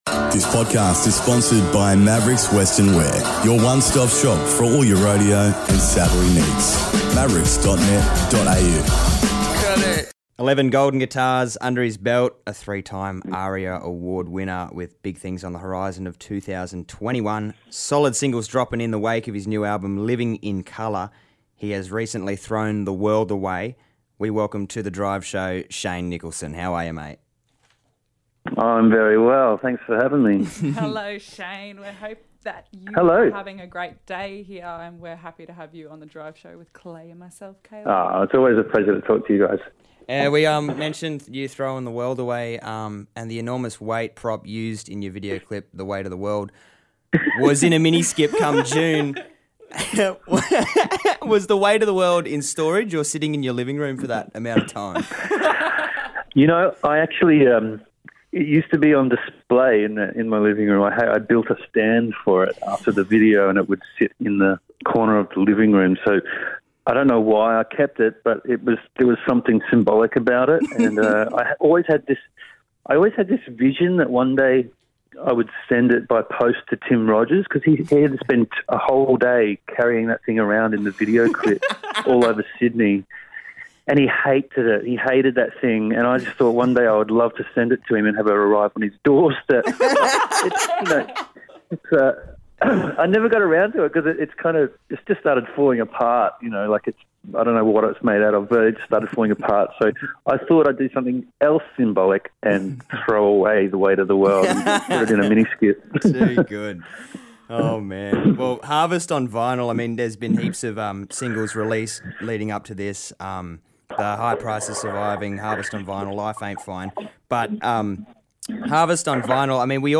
Shane Nicholson Joins the drive team for a yarn about the newest album Living In Colour.